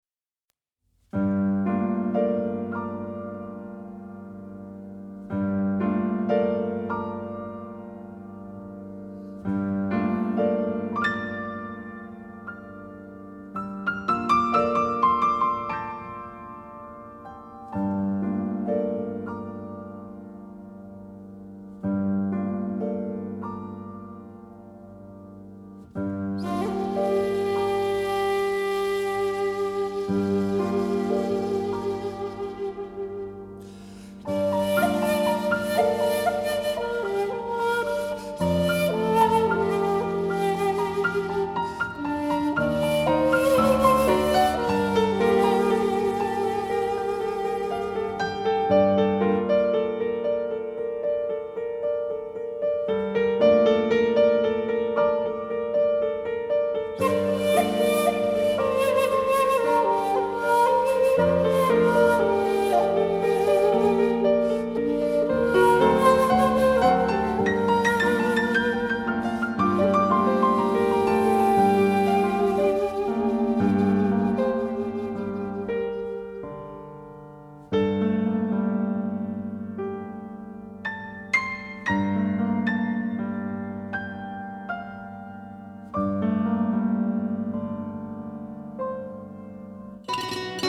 鋼琴超凡的獨奏表現力，帶給這些熟稔旋律金玉璀璨的外觀。
色彩繽紛的各色民樂器透過黑白琴鍵之間的交錯，豐富而絢麗的對話，令人目不暇接！